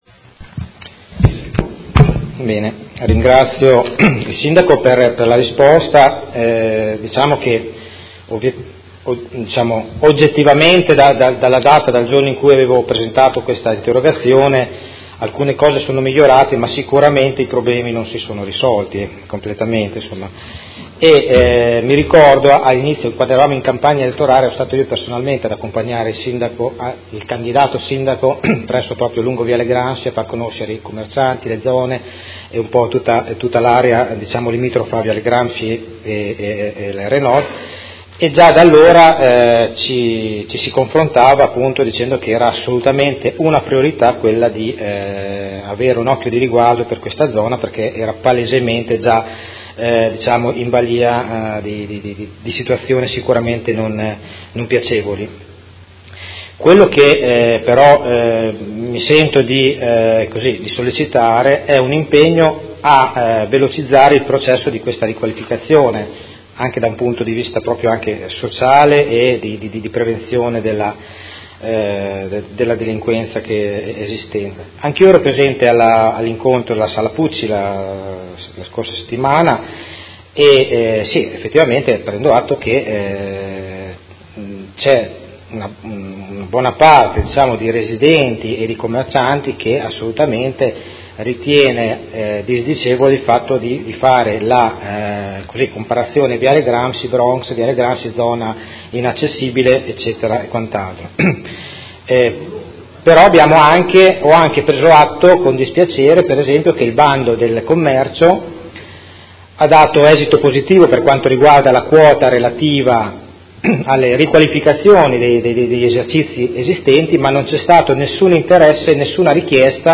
Seduta del 19/10/2017 Replica a risposta Sindaco. Interrogazione del Gruppo Consiliare Art.1 – MDP avente per oggetto: Persistenza dei gravi problemi di ordine pubblico, sicurezza e vivibilità della zona R-Nord-Viale Gramsci, Parco XXII Aprile e Via Del Mercato.